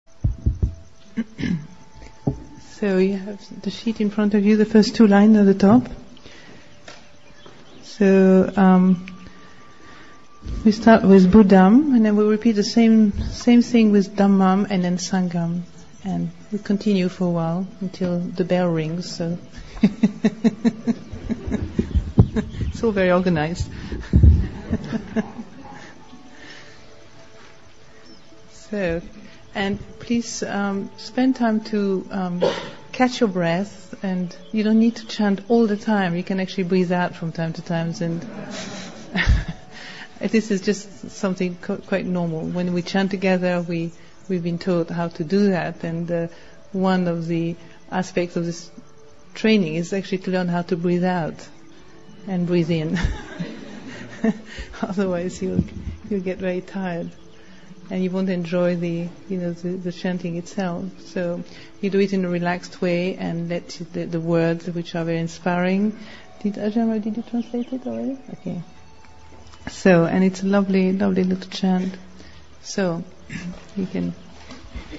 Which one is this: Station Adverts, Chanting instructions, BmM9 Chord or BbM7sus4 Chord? Chanting instructions